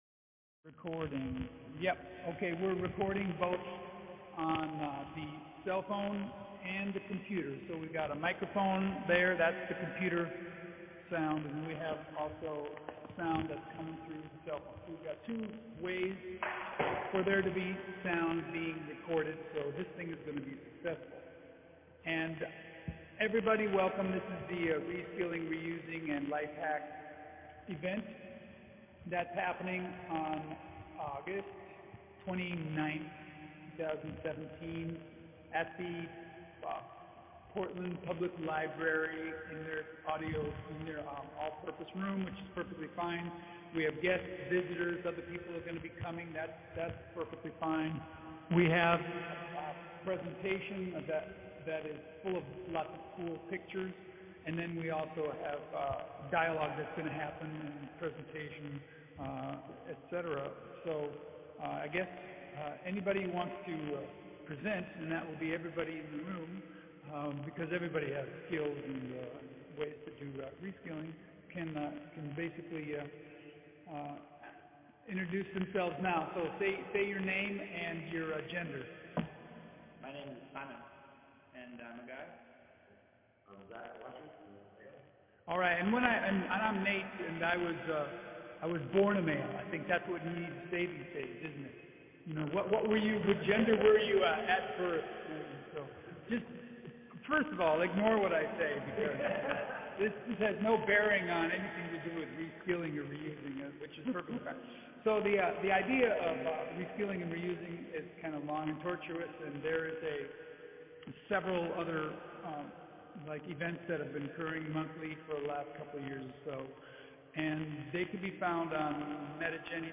Reskilling and ReUsing and LifeHack Meet Space Event
As we do every last-Tuesday of the month until 7:30pm, we will meet, greet, converse in the all-purpose room in the lower level of the library. This is an opportunity to share a passion for growing in wisdom about what practical things we can do about anything we can think of.